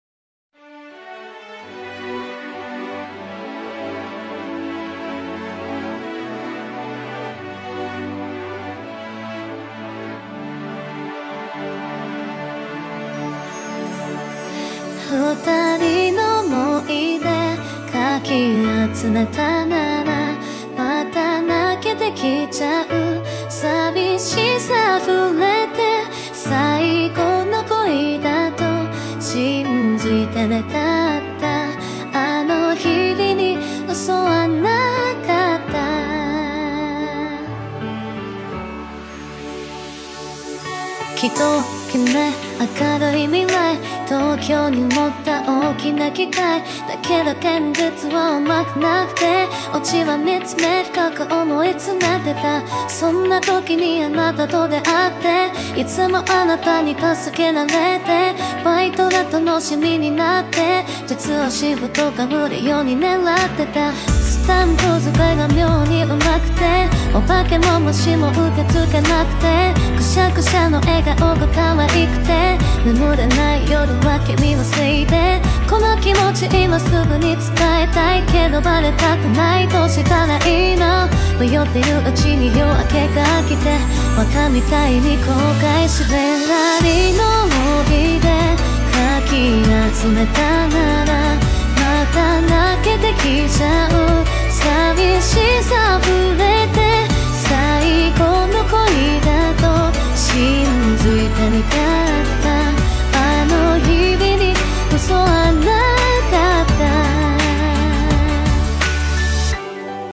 以上来自新低模训练下的推理模型，这里也无偿分享给大家尝试训练好的推理模型供大家研究学习。